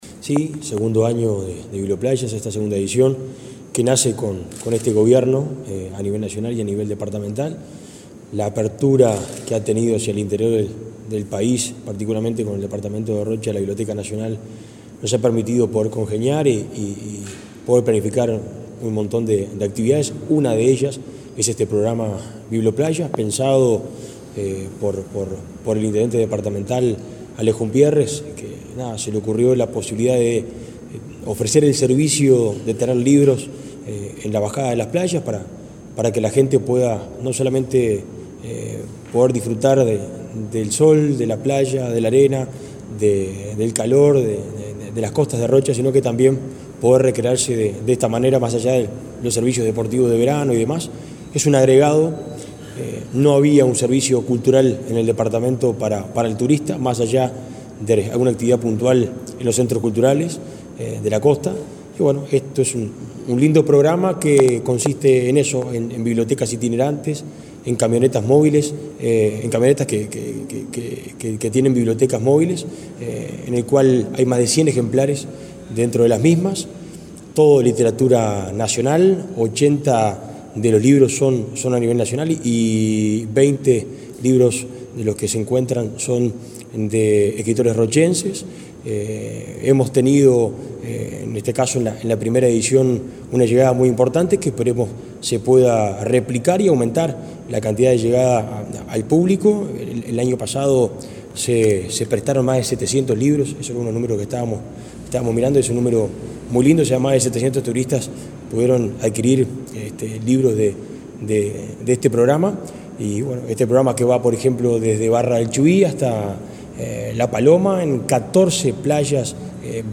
Entrevista al director de Cultura de la Intendencia de Rocha, Fabricio Núñez